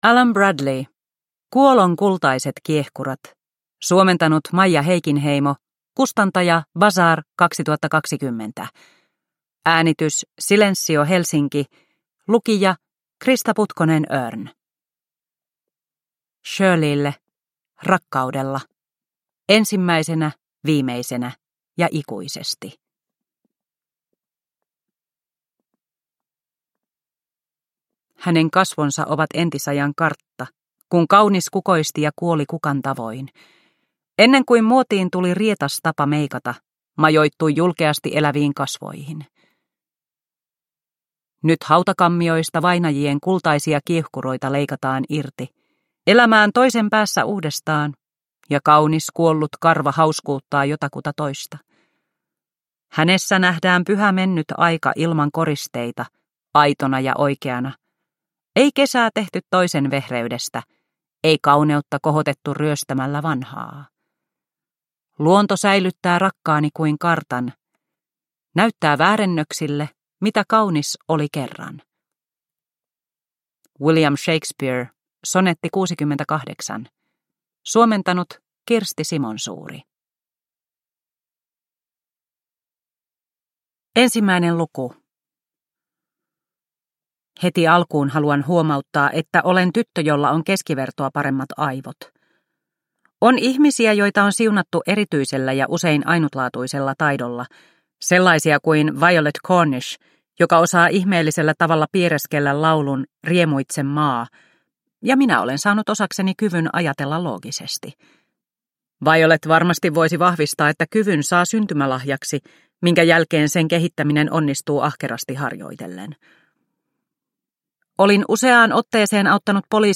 Kuolon kultaiset kiehkurat – Ljudbok – Laddas ner